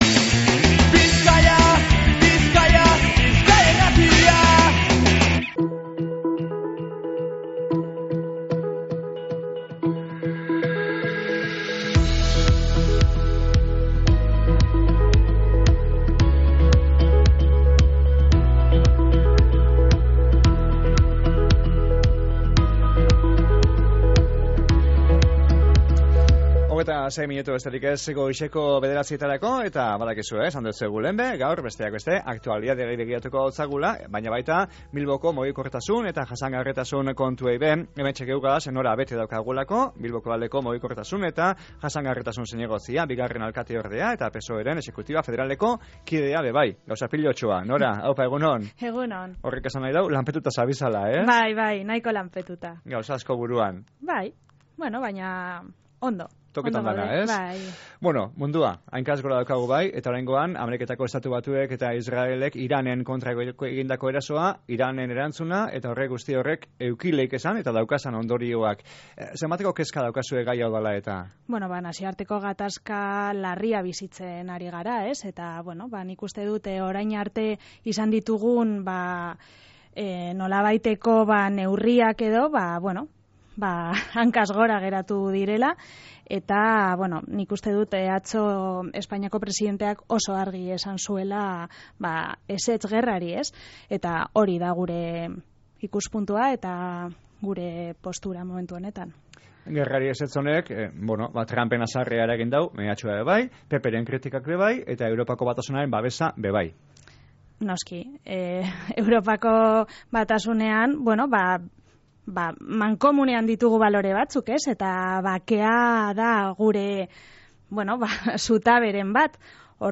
Gaur Bilboko Udaleko alkateordea eta Mugikortasun eta Jasangarritasun zinegotzia izan dogu gure estudioan